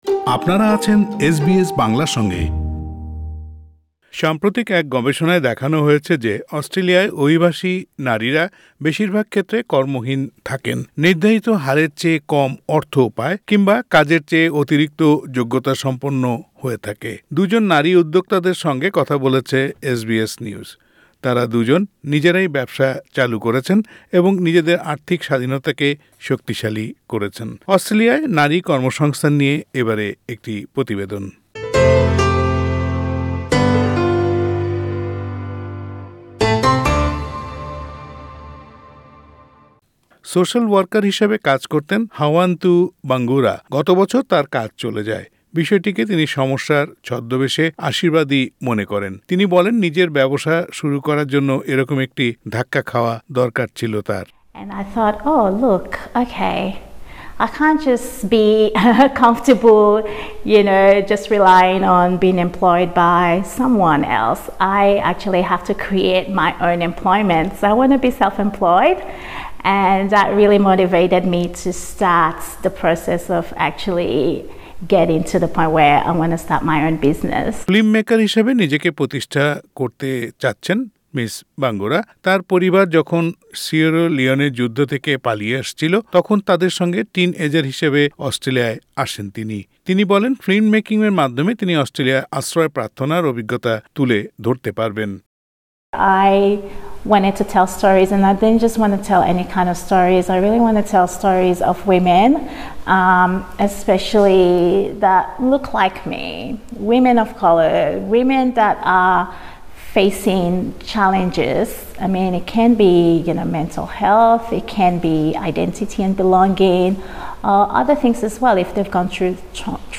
গবেষণায় দেখা গেছে যে, অস্ট্রেলিয়ায় অভিবাসী পটভূমির নারীরা বেশিরভাগ ক্ষেত্রে কর্মহীন থাকে, নির্ধারিত হারের চেয়ে কম অর্থ পায় কিংবা কাজের চেয়ে অতিরিক্ত যোগ্যতাসম্পন্ন হয়ে থাকে। দু’জন নারী উদ্যোক্তার সঙ্গে কথা বলেছে এসবিএস নিউজ। তারা দু’জন নিজেরাই ব্যবসা চালু করেছেন এবং নিজেদের আর্থিক স্বাধীনতাকে শক্তিশালী করছেন।